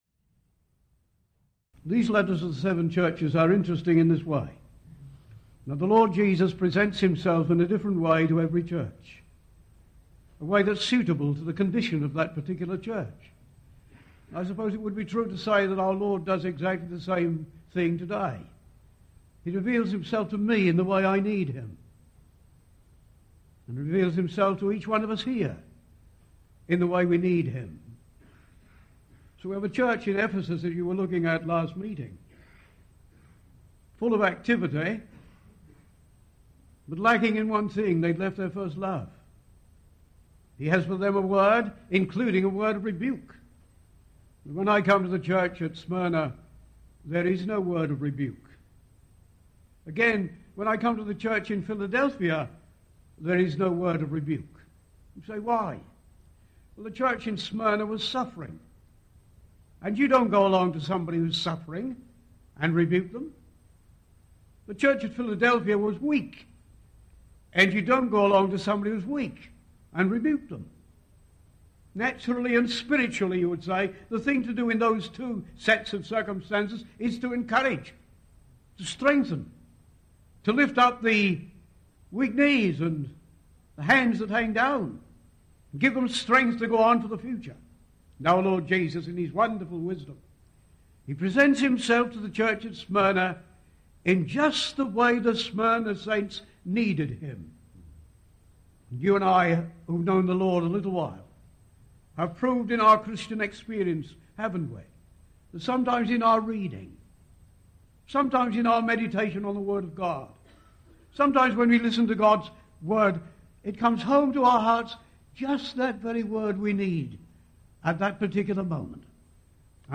He draws lessons from their severe suffering for Christ. (Recorded in England) (Photo: Izmir, Turkey, once known as Smyrna)
Historical Ministry Sermons